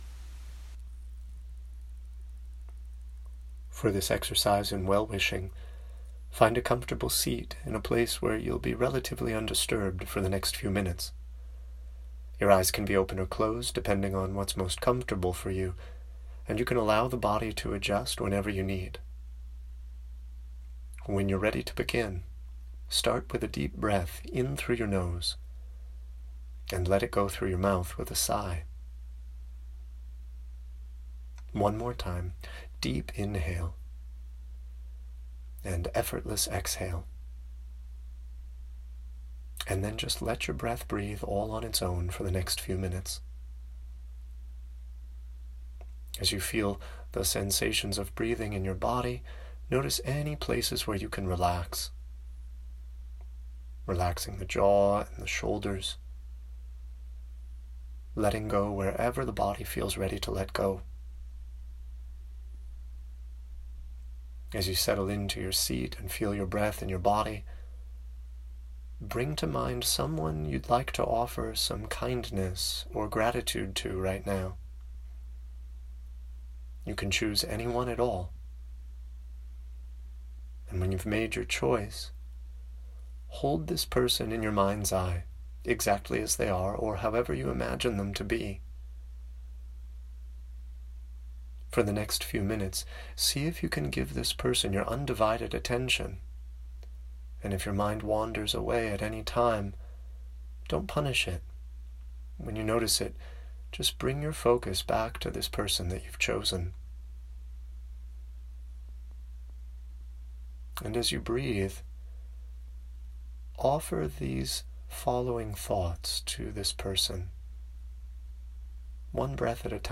Enjoy several meditations.